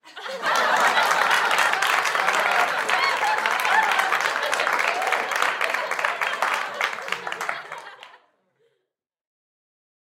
Звуки смеха
Смех зрителей и аплодисменты М+Ж